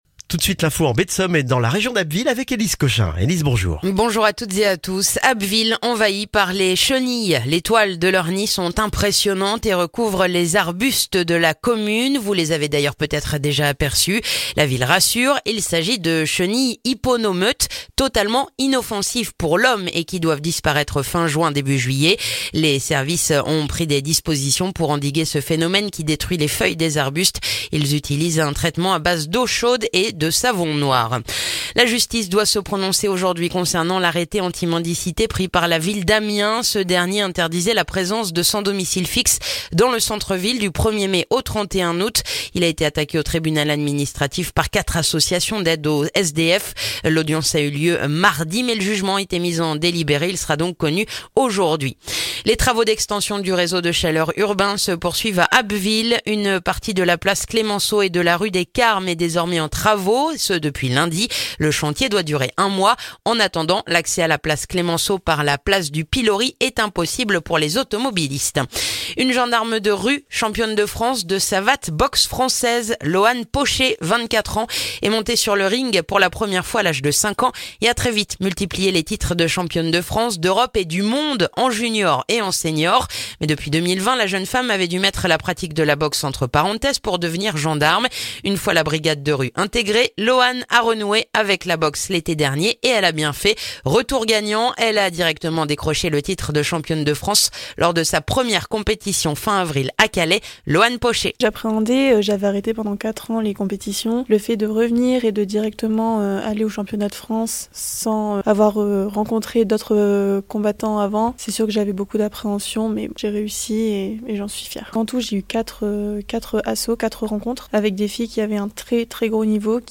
Le journal du jeudi 16 mai en Baie de Somme et dans la région d'Abbeville